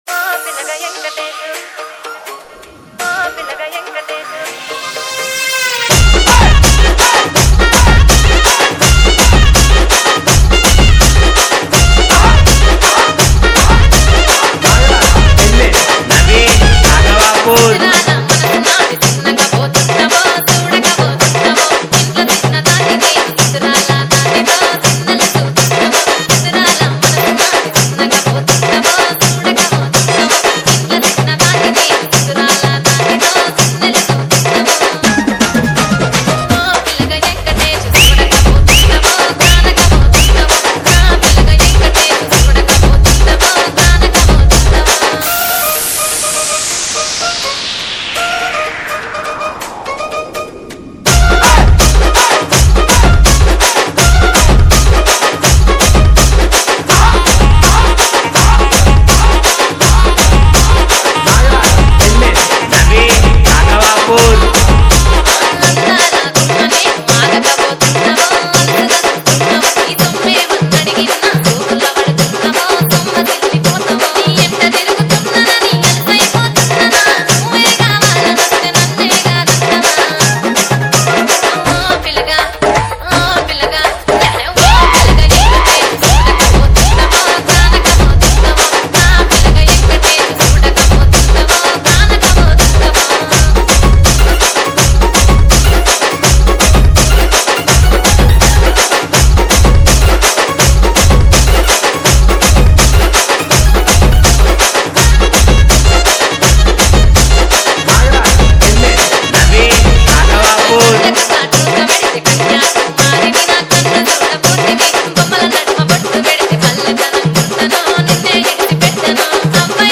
Telugu Love Dj Remix